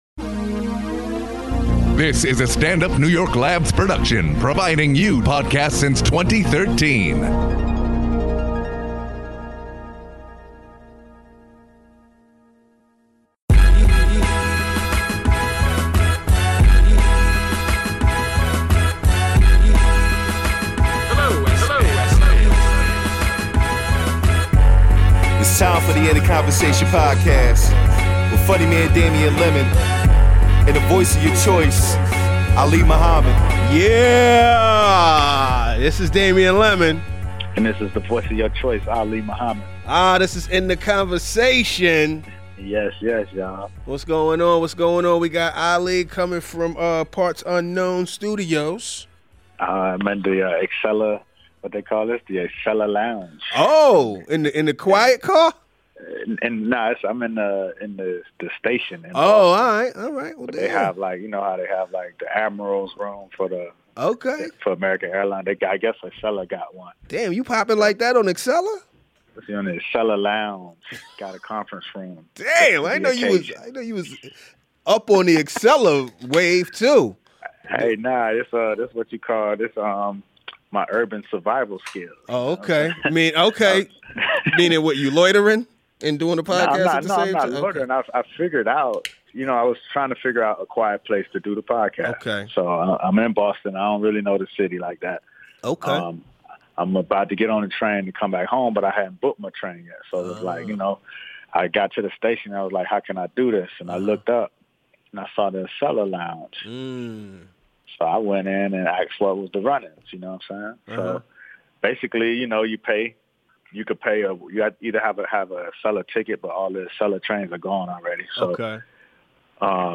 calls in from Boston